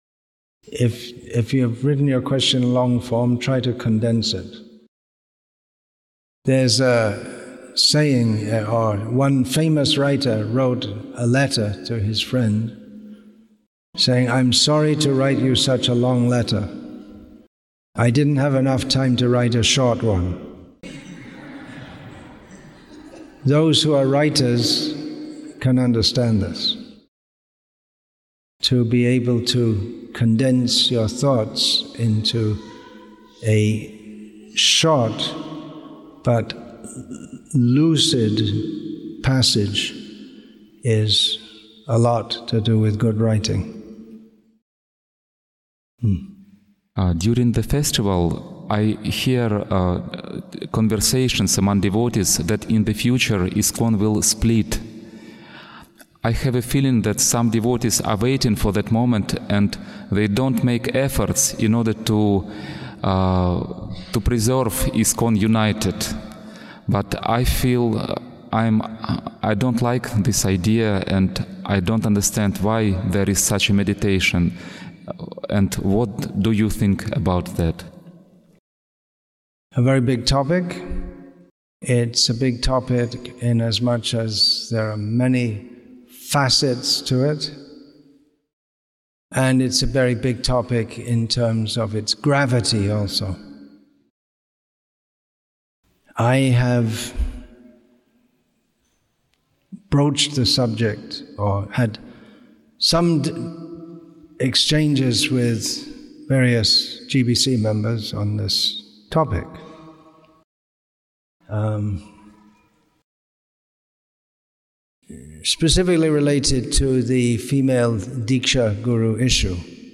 Questions And Answers Session, Part 7